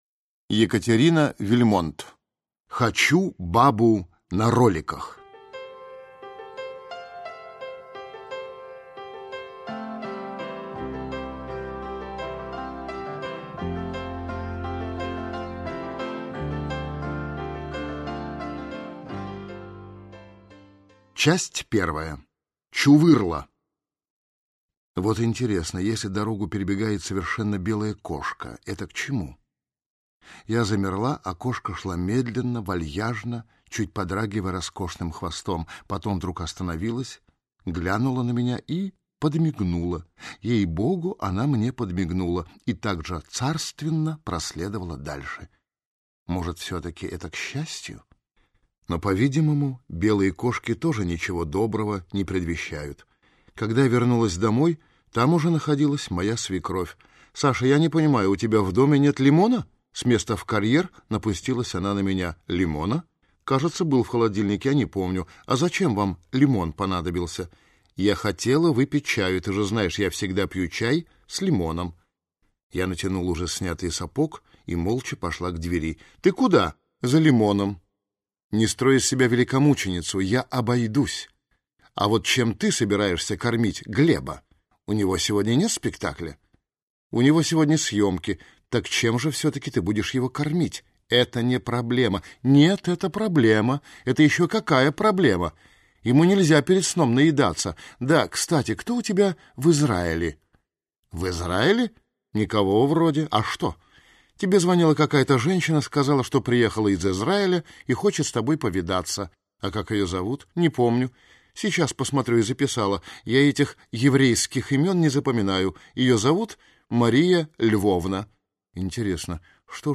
Aудиокнига Хочу бабу на роликах!